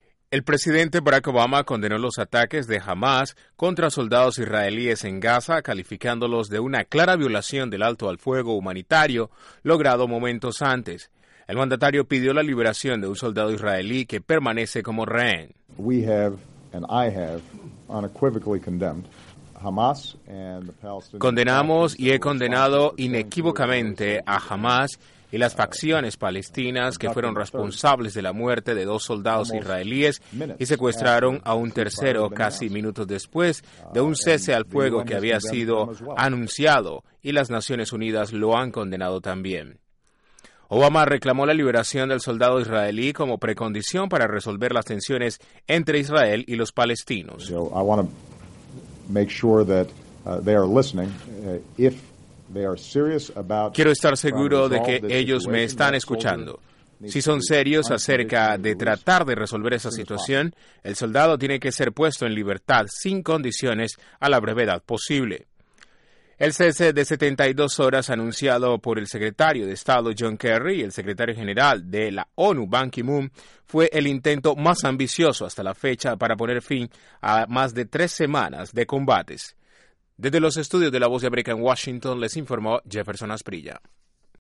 INTRO: Estados Unidos condenó a Hamás por violar un cese al fuego de 72 horas en Gaza. El presidente Obama dijo que la incapacidad de Hamas de controlar sus facciones lastiman su capacidad para negociar treguas. Desde la Voz de América en Washington informa